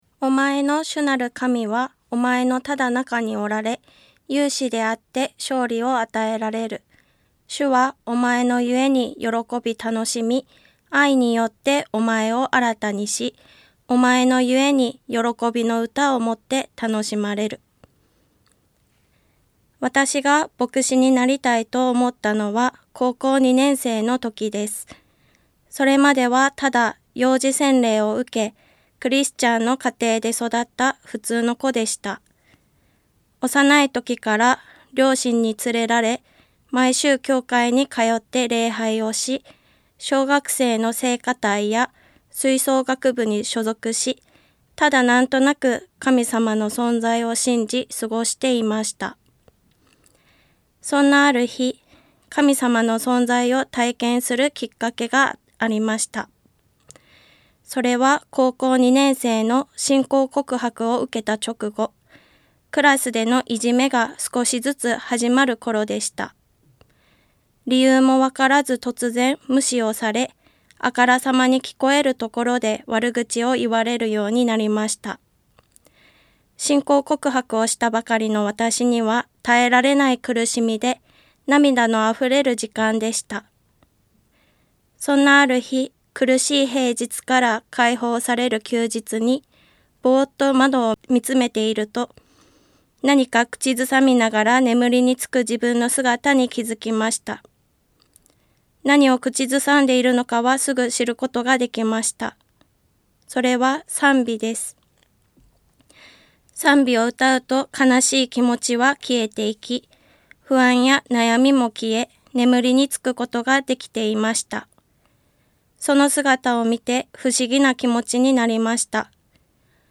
ラジオ番組